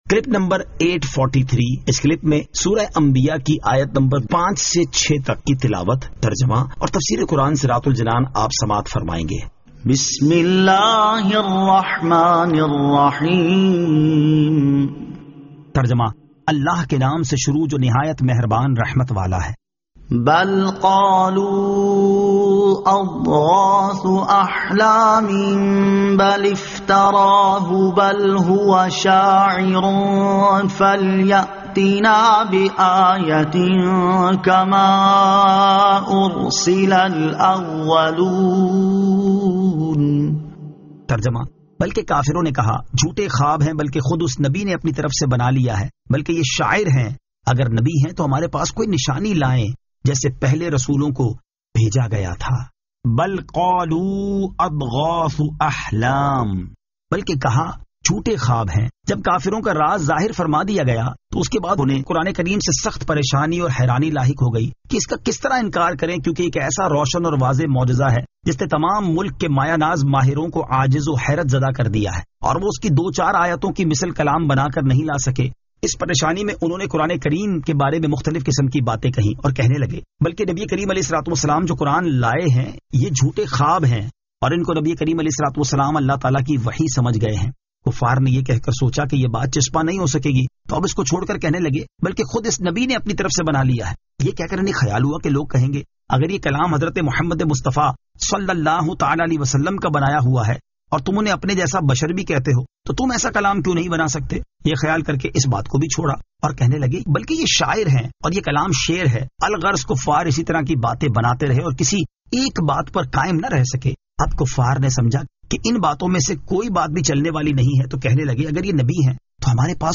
Surah Al-Anbiya 05 To 06 Tilawat , Tarjama , Tafseer